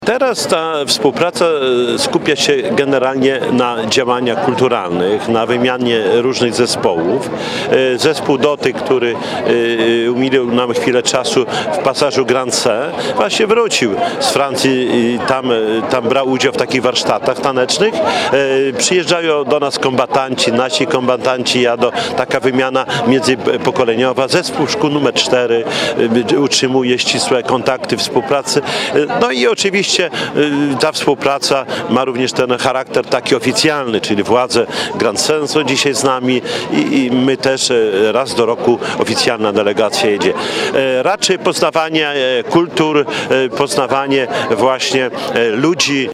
Głos zabrali Czesław Renkiewicz, prezydent Suwałk i mer Grande-Synthe, Damien Careme.